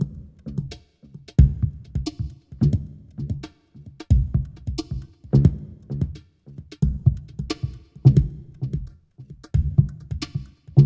TRIP-HOPPI-R.wav